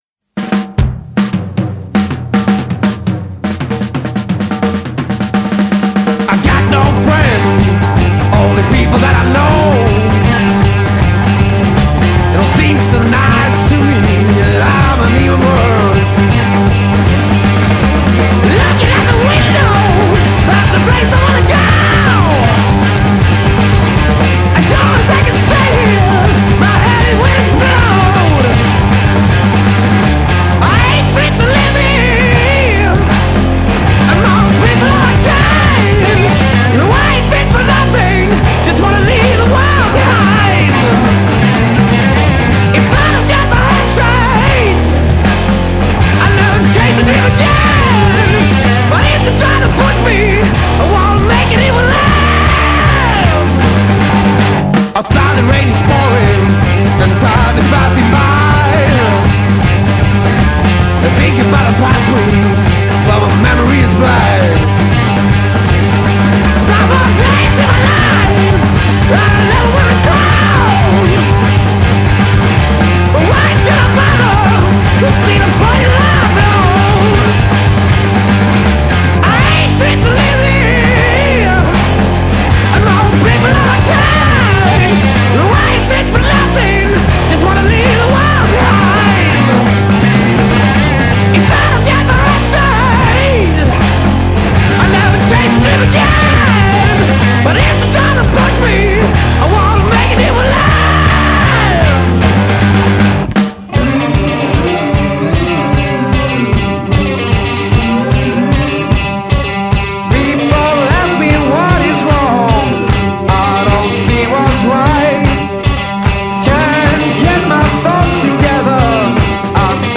Hard Rock / Blues Rock / Psychedelic Rock